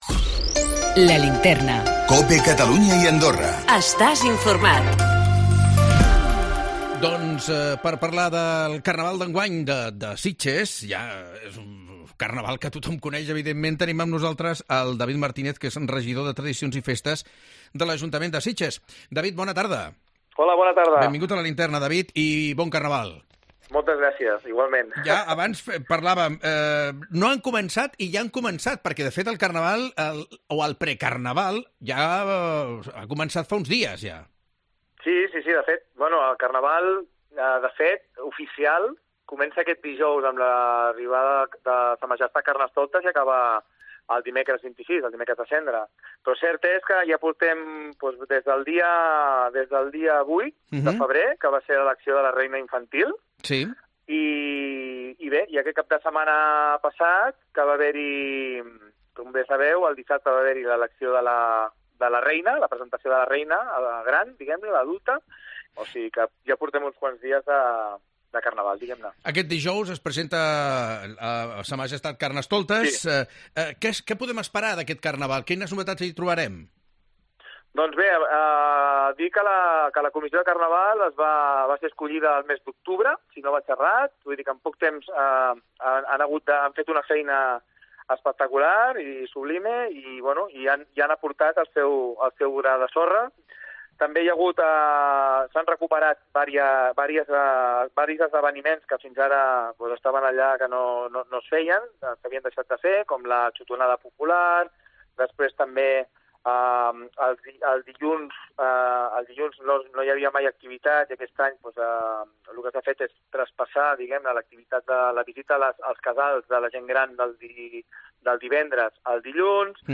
Parlem amb David Marínez, regisdor de tradicions i festes de l'ajuntament de Sitges